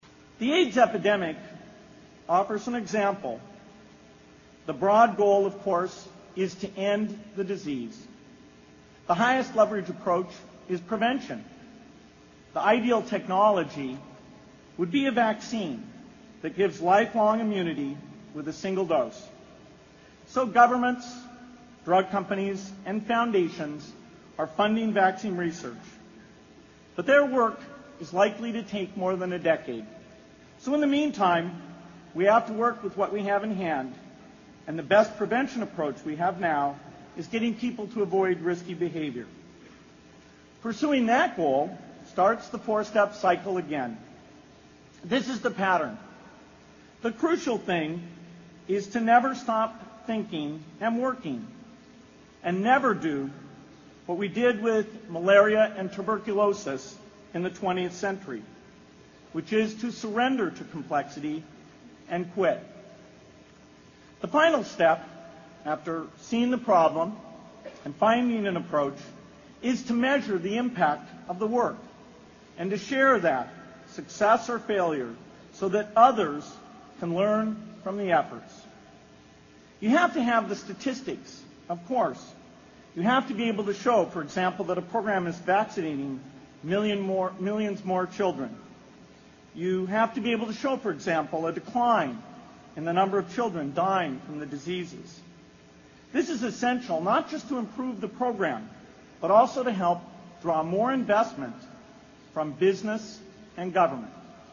在线英语听力室偶像励志英语演讲 第98期:如何解决这个世界最严重的不平等(8)的听力文件下载,《偶像励志演讲》收录了娱乐圈明星们的励志演讲。